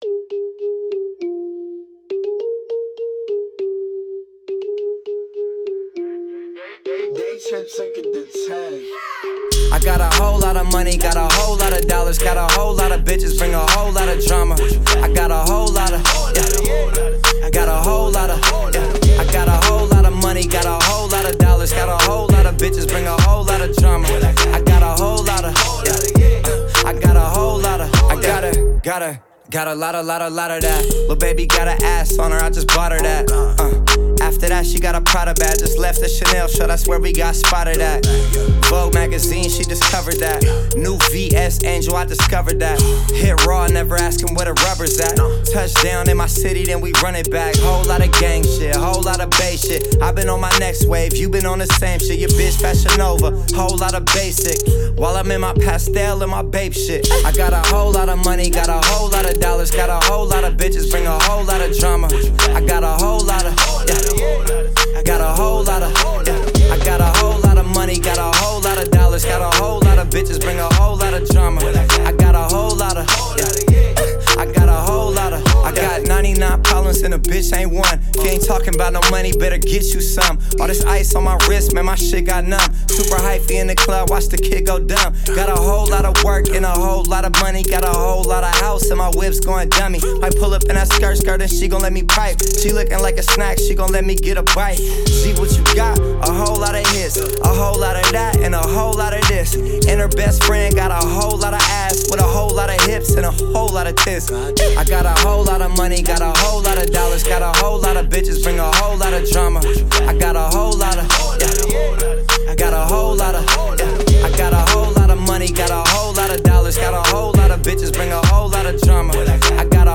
это энергичная хип-хоп композиция